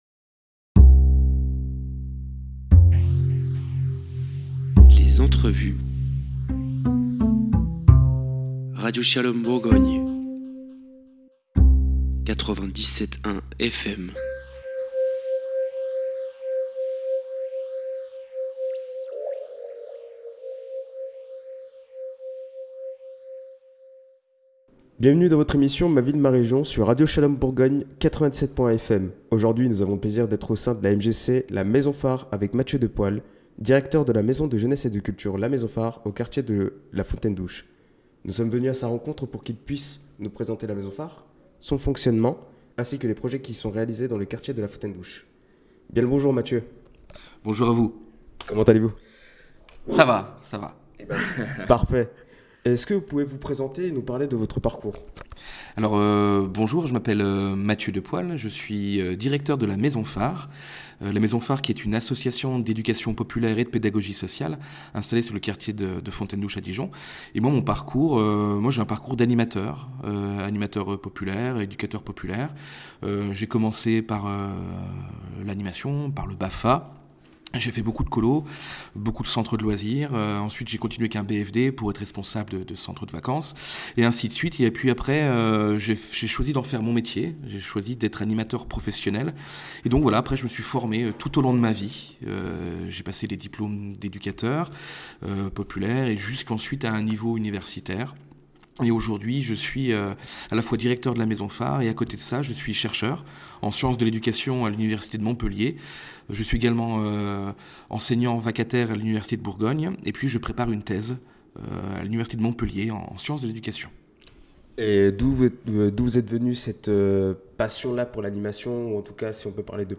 Un échange dynamique au service de la vie locale.